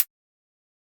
Index of /musicradar/ultimate-hihat-samples/Hits/ElectroHat C
UHH_ElectroHatC_Hit-02.wav